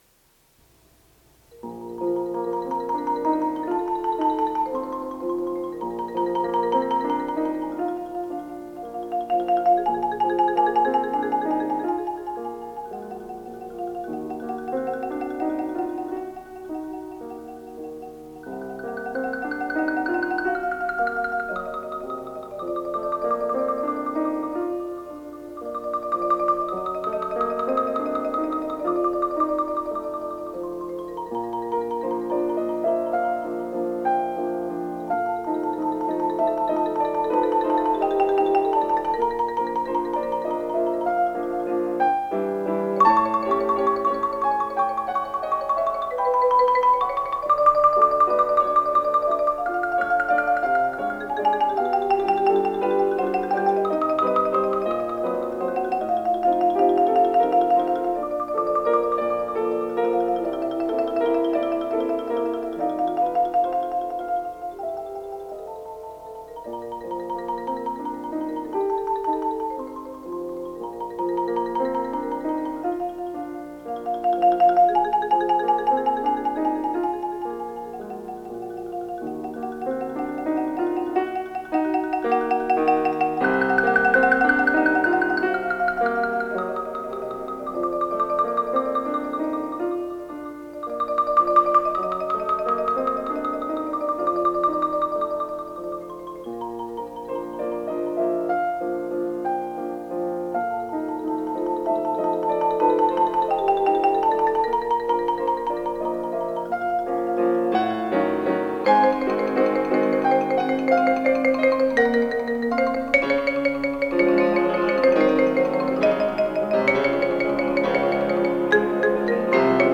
非常に縦に深く、パイプをよく共鳴させ、重厚で